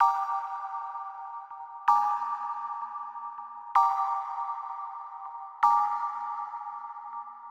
MB - Loop 2 - 64BPM.wav